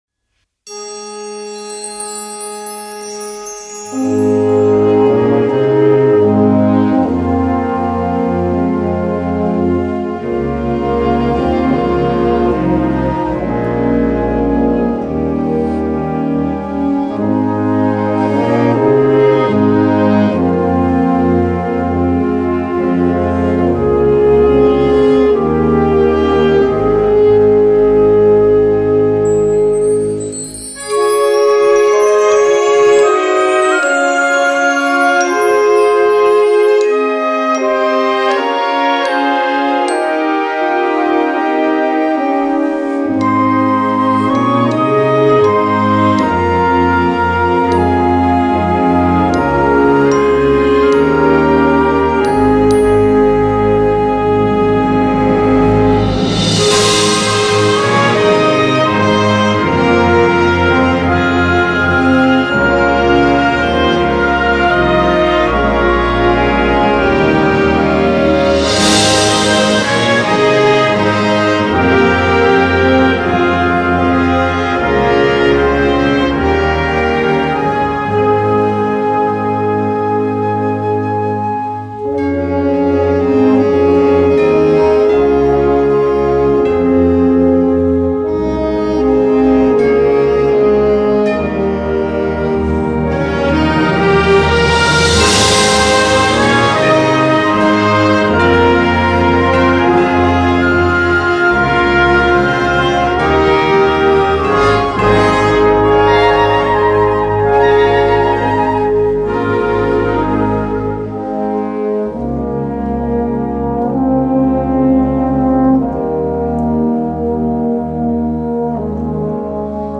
Gattung: Concert Band
Besetzung: Blasorchester